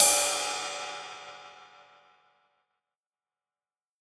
Crashes & Cymbals
22inchride.wav